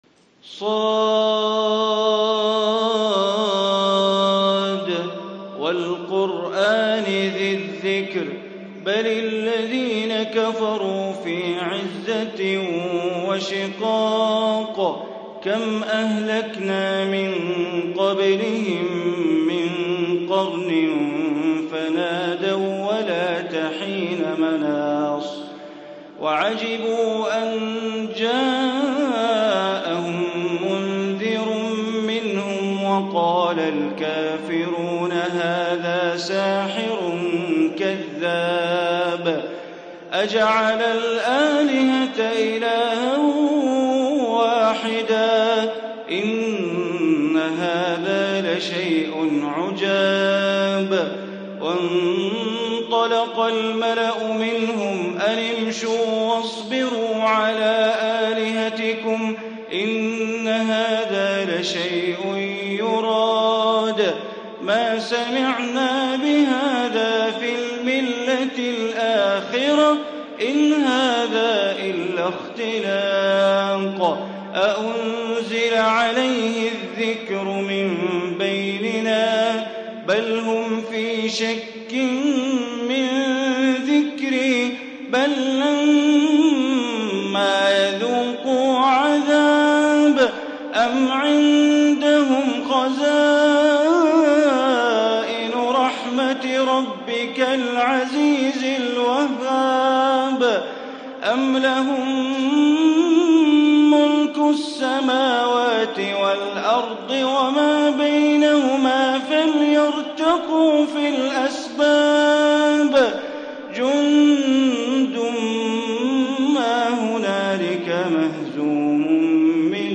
سورة ص > مصحف الحرم المكي > المصحف - تلاوات بندر بليلة